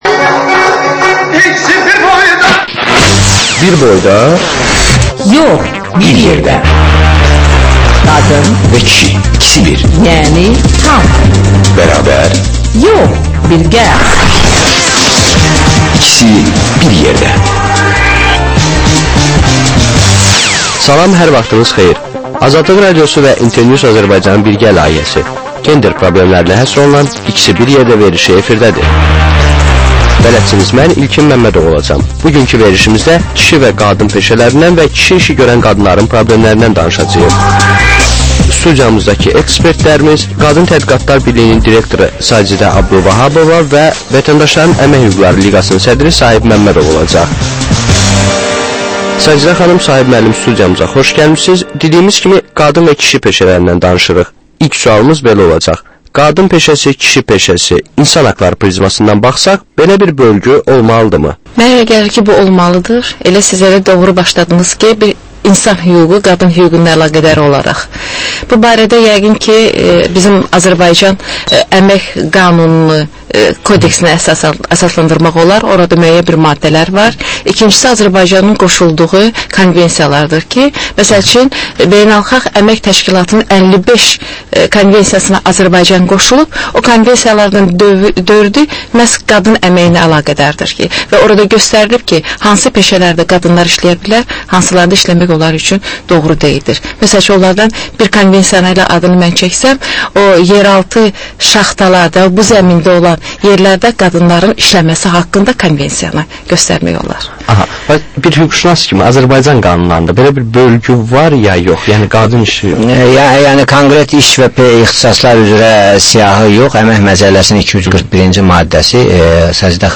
Həftənin aktual məsələsi haqda dəyirmi masa müzakirəsi